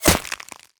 bullet_impact_ice_06.wav